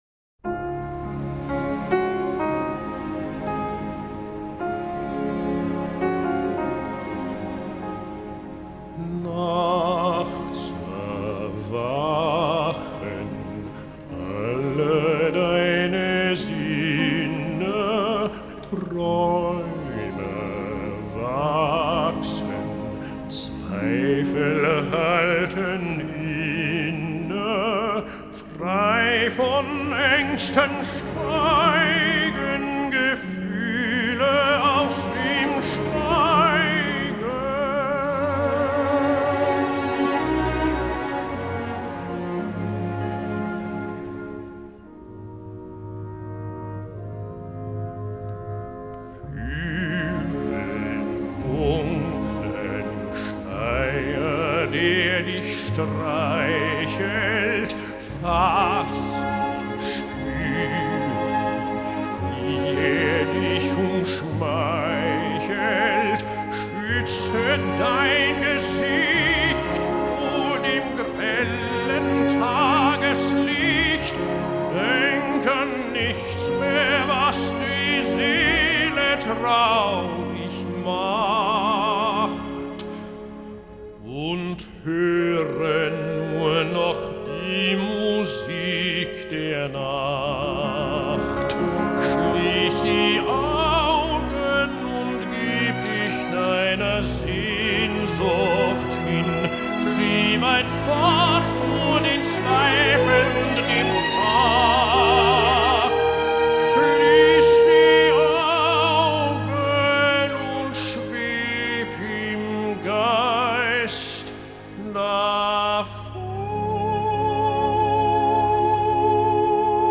freischaffender Musicalsänger
Arie des Phantoms
Basel 1996